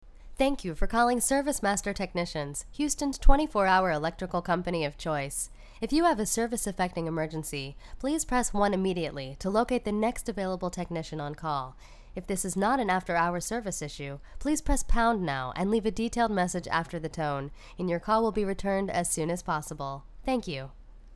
You can set up a locator service that would call up to four different numbers until someone was reached. What if your message sounded like this?